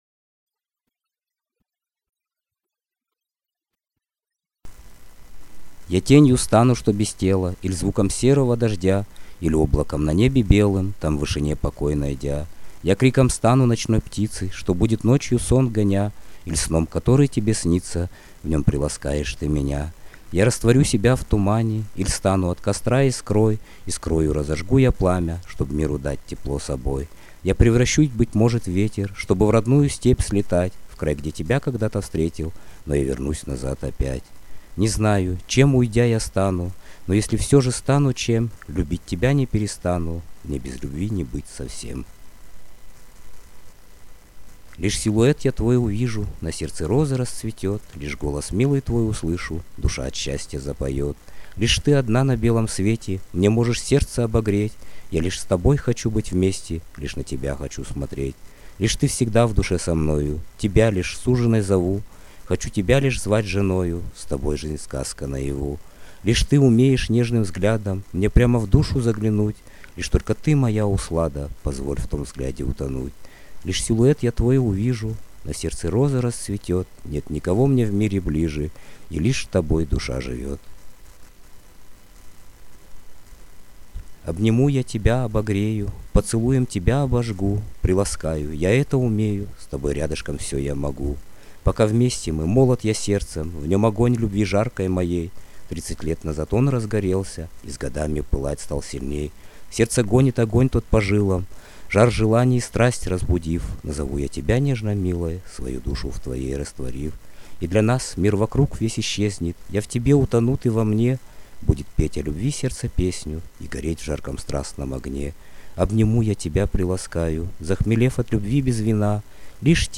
Стихи Поэзия